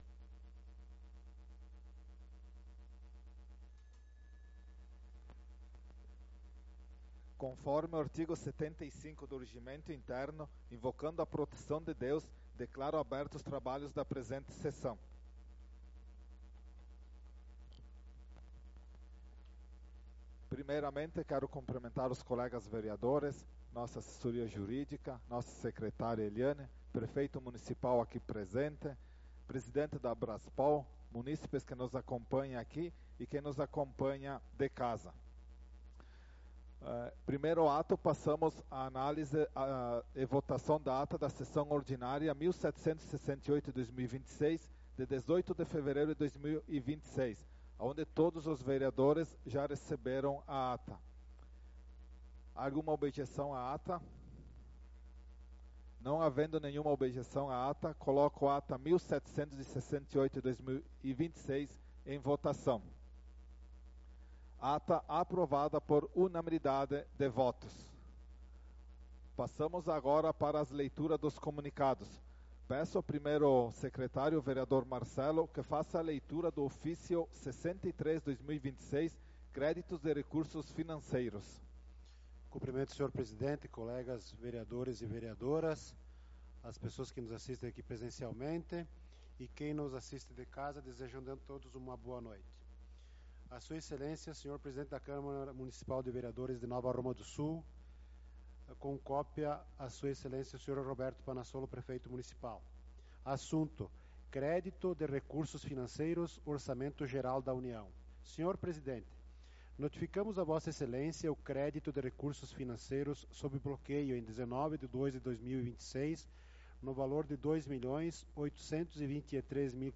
Sessão Ordinária do dia 25/02/2026